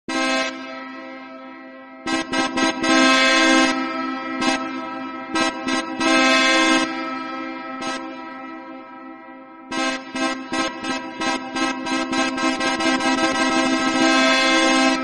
Ringtone Car Horn
Category Sound Effects